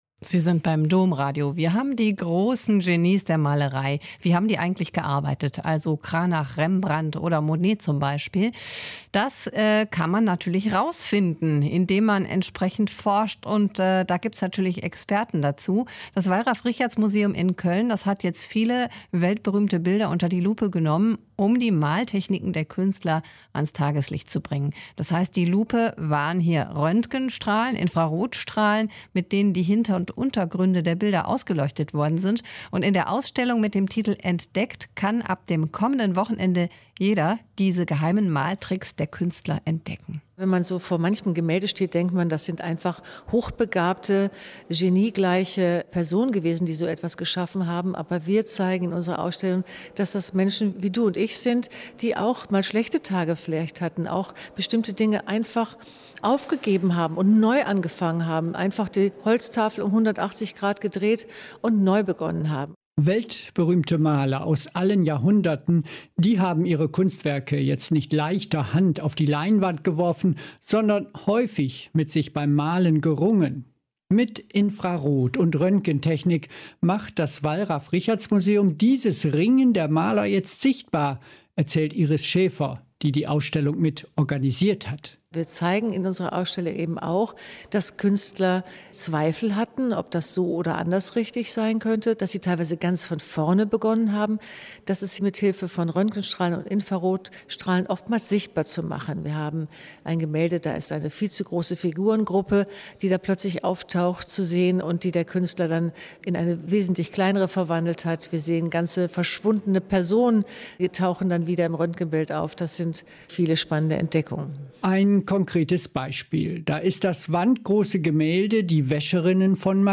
Sendemitschnitt / Beitrag vom 08.10.2021 Ausstellung 'Entdeckt! Maltechniken von Martini bis Monet' im Wallraf-Richartz-Museum